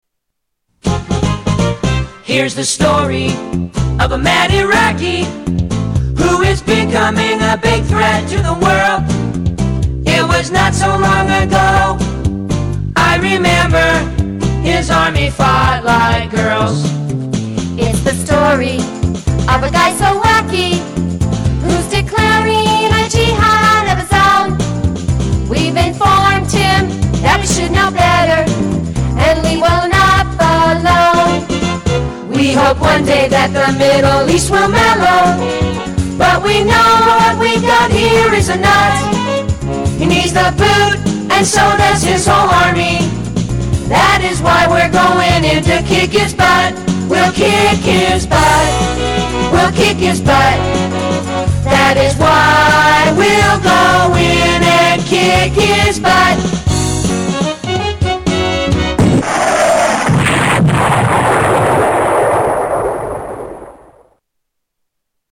Parodies Songs Comedy Spoofs